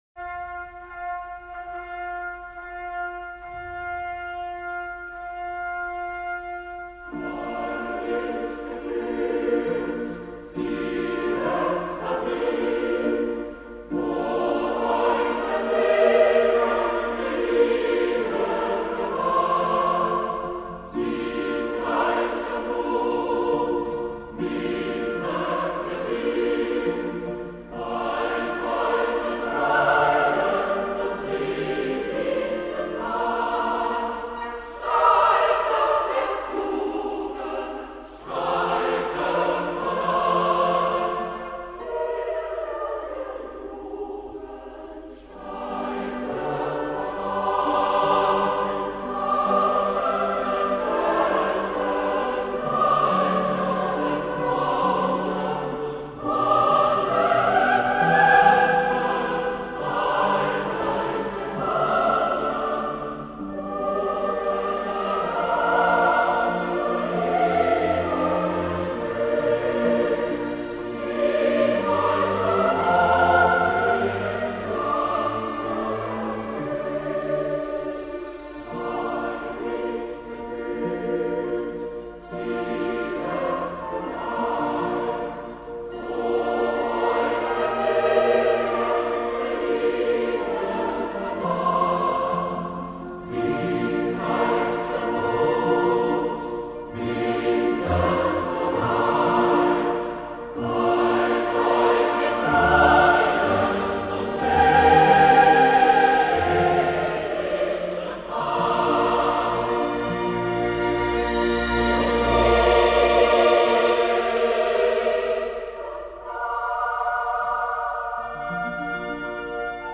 Marcha Nupcial de la ópera "Lohengrin" R. Wagner Coro